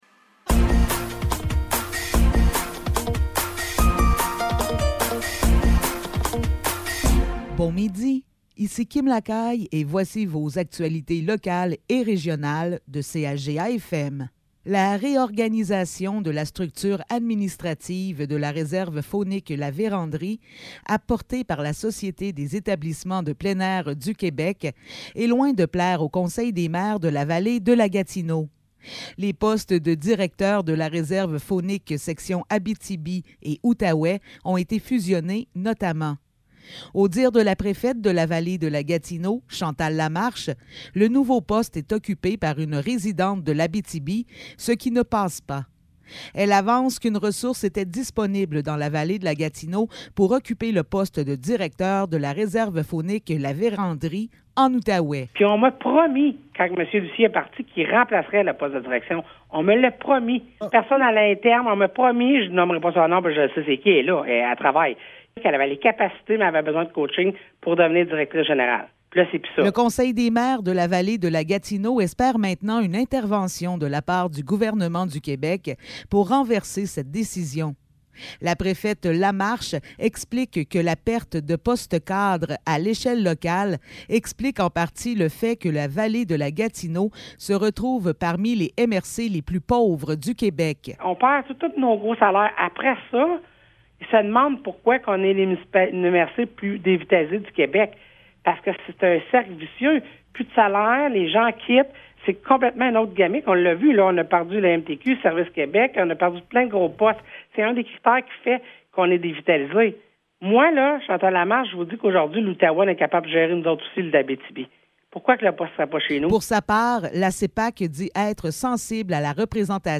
Nouvelles locales - 8 septembre 2023 - 12 h